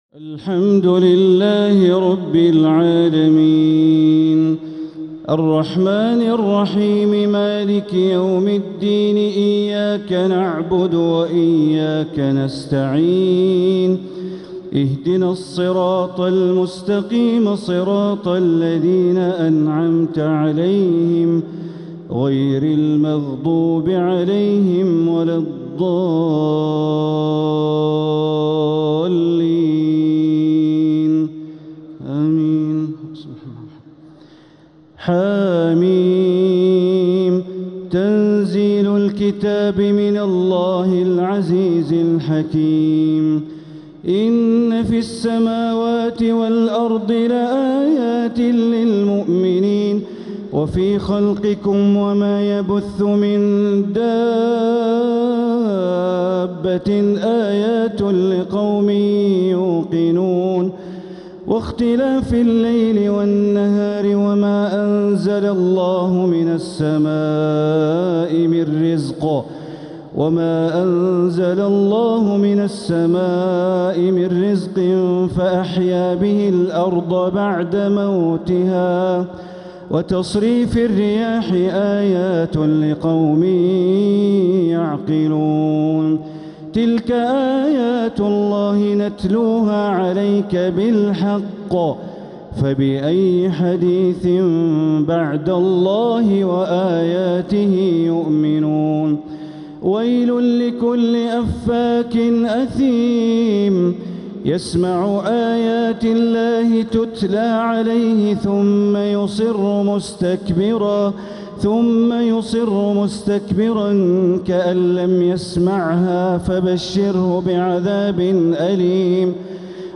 تهجد ليلة 26 رمضان 1447هـ سورة الجاثية والأحقاف ومحمد > تراويح 1447هـ > التراويح - تلاوات بندر بليلة